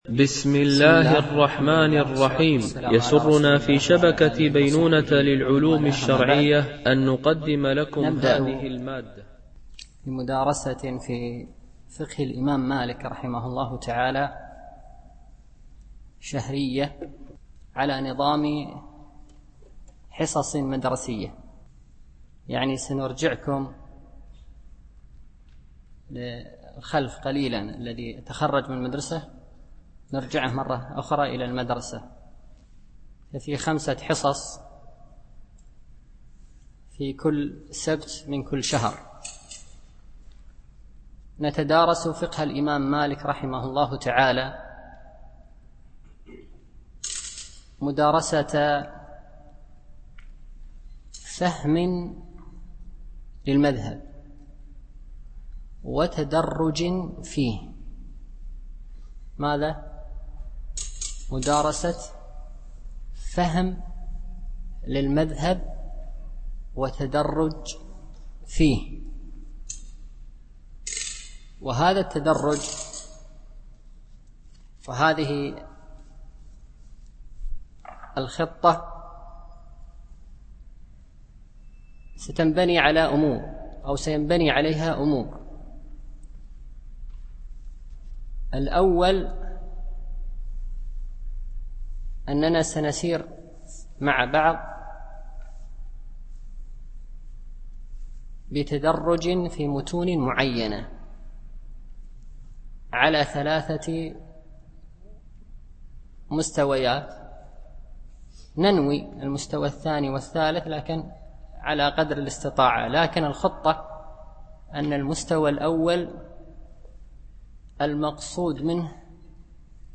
شرح الفقه المالكي ( المستوى الأول - متن الأخضري ) - الدرس 1